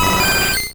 Cri de Leveinard dans Pokémon Rouge et Bleu.